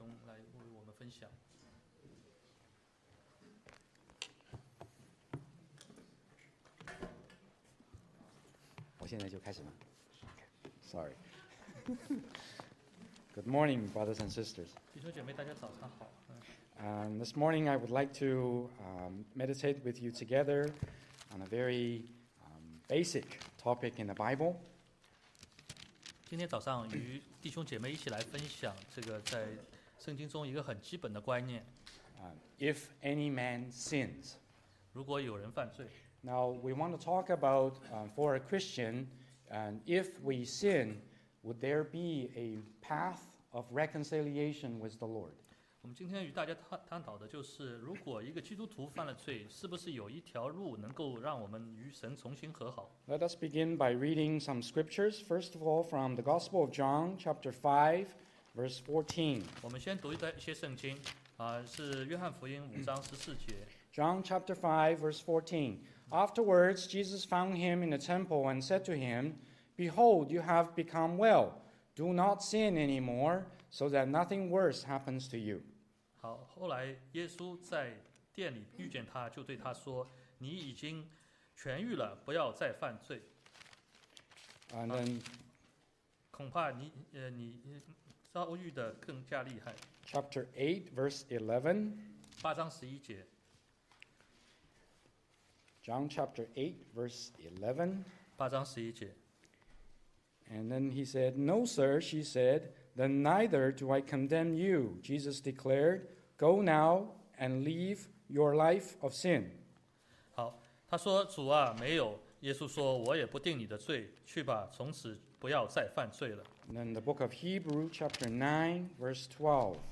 東區基督教會主日崇拜講道信息